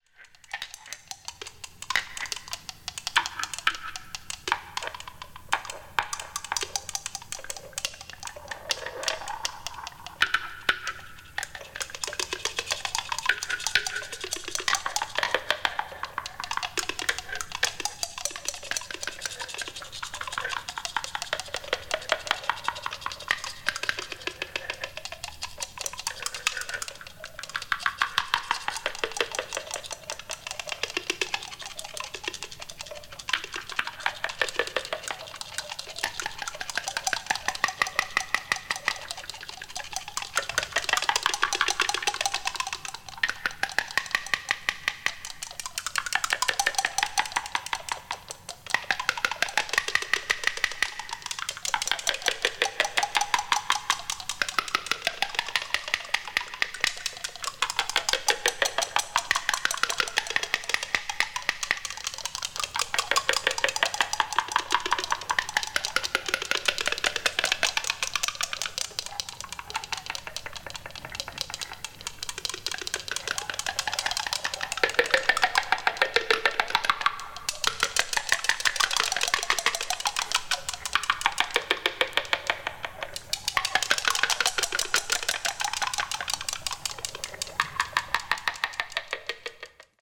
media : EX/EX(some slightly surface noises.)